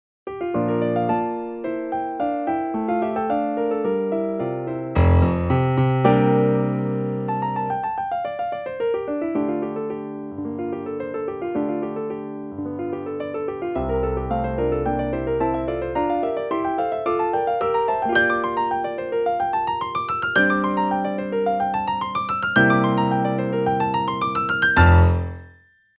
No other effects, enhancement or compression is used in these demos.
Piano_Debussy_Prelude_Hall_DRY.mp3